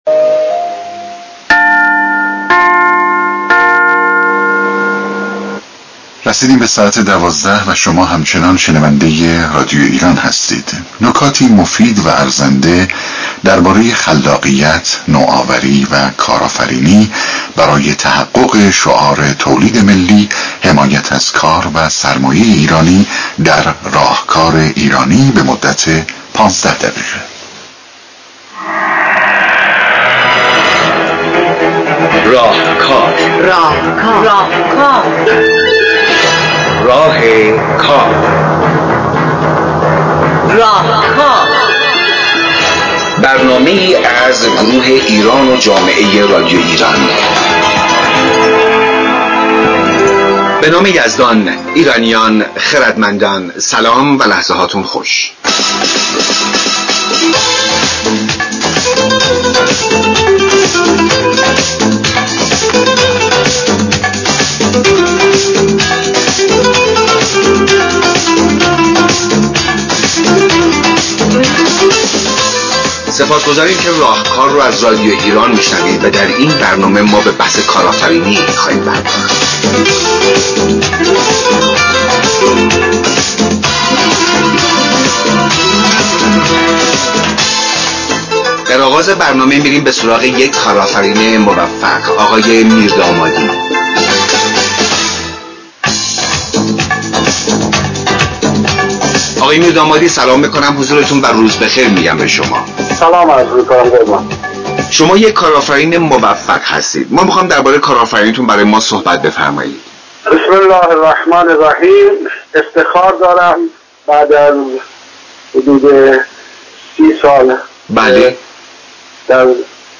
این برنامه در استودیو رادیو ایران ضبط و در اردیبهشت 139 1 از صدای جمهوری اسلامی ایران - برنامه راهکار پخش گردیده است